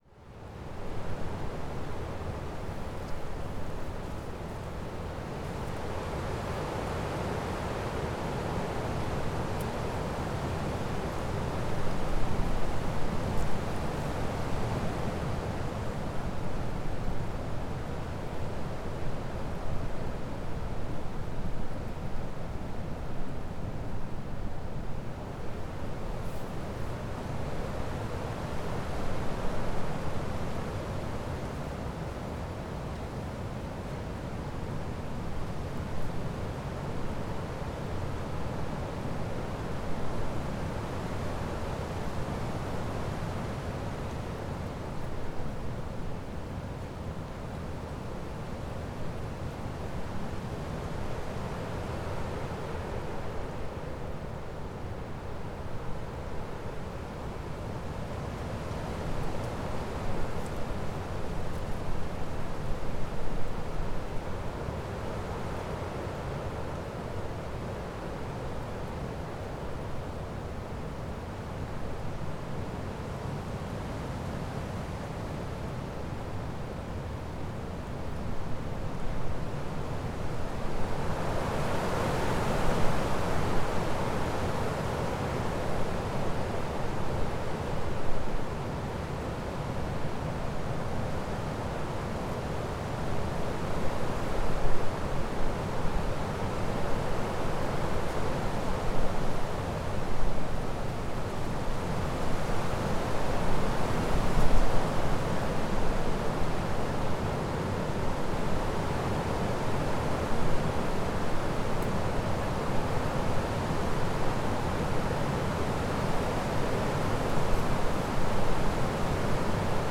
Wind in trees — gale blowing — in open woods high in Teign Gorge (3) Sound Effect — Free Download | Funny Sound Effects